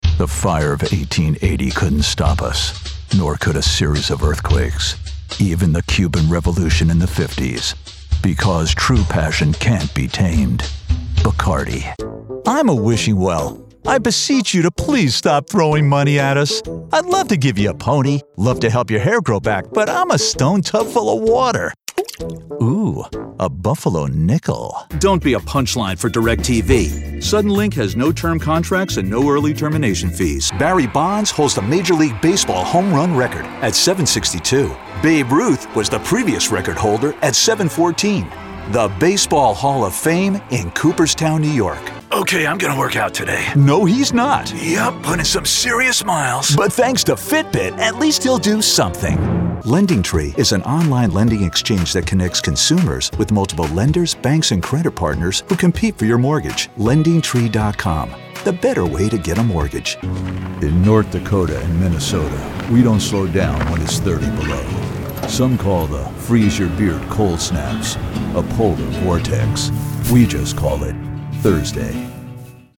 Never any Artificial Voices used, unlike other sites.
Adult (30-50) | Older Sound (50+)
0319Commercial_Demo.mp3